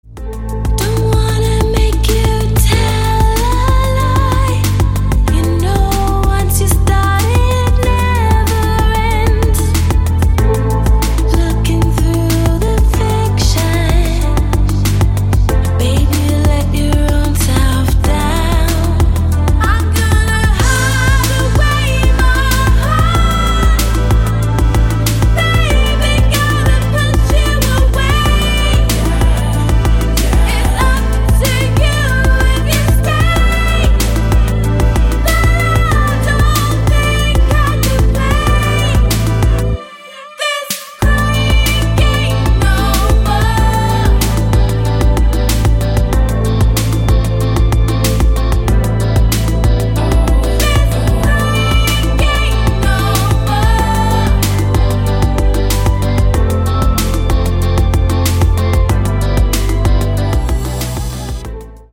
• Качество: 256, Stereo
женский вокал
Electronic
спокойные
chillout
Downtempo
клавишные
чувственные